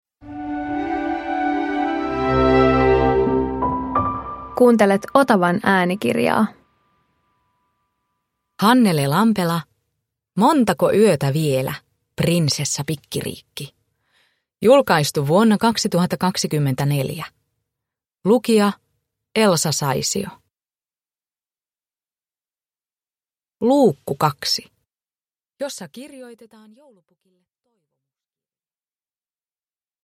Montako yötä vielä, Prinsessa Pikkiriikki 2 – Ljudbok